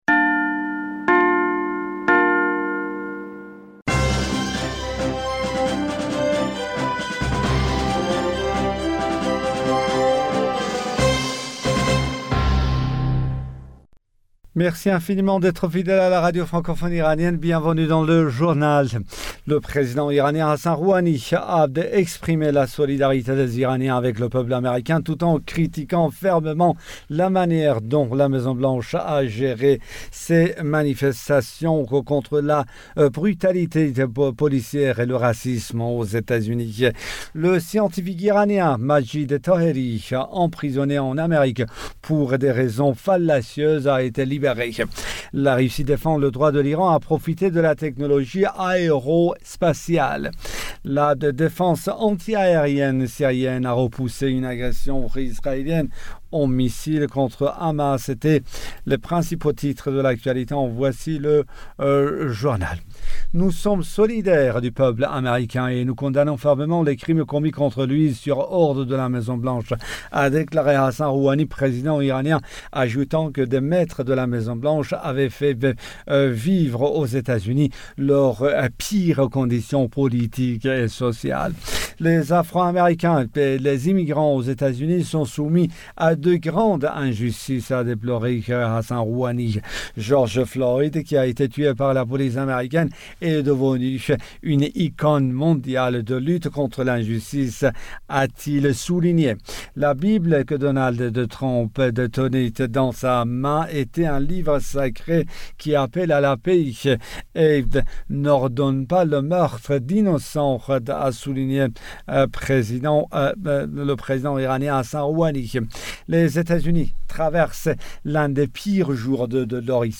Bulletin d'information du 05 Juin 2020